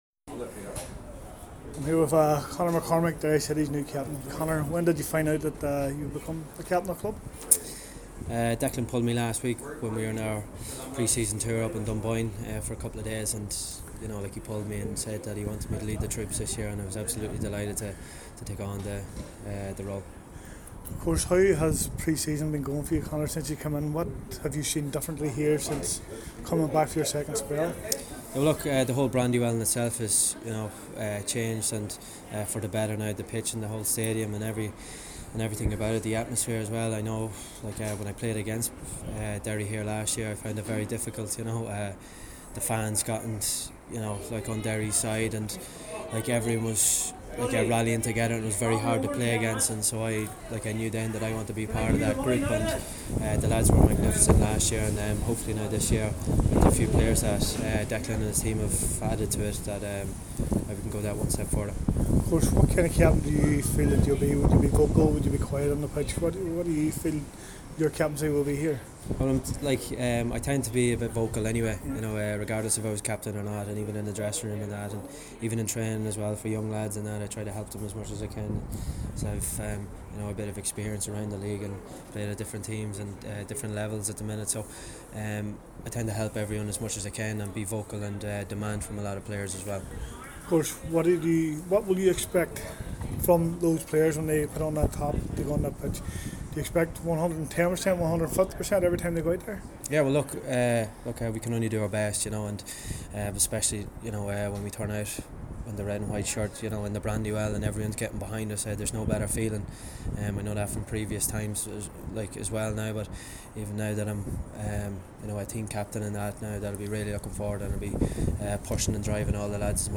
at today season launch at the Brandywell